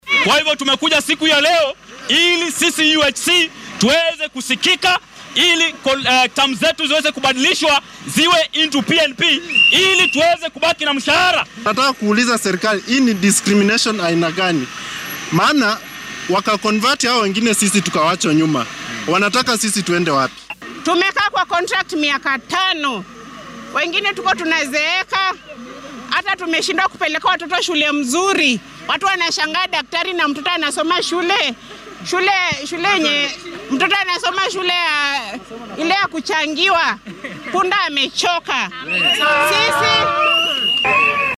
Qaar ka mid ah kalkaaliyaashan oo dareenkooda la wadaagay warbaahinta ayaa yidhi.